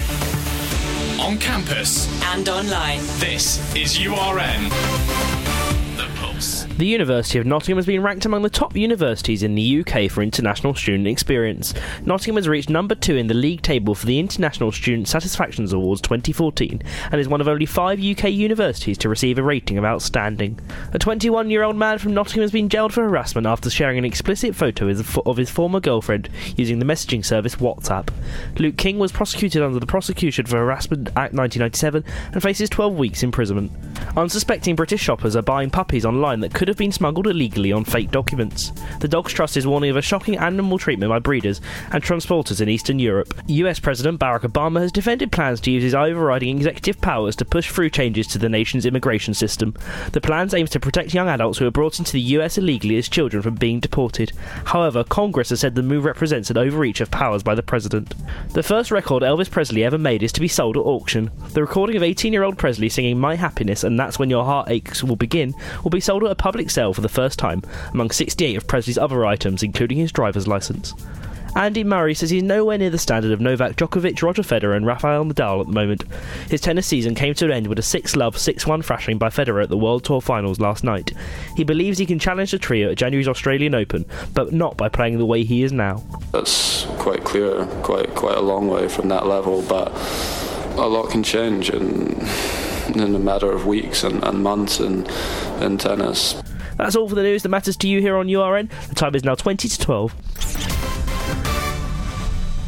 All of your latest headlines on URN, including Andy Murray himself on his thrashing by Rodger Federer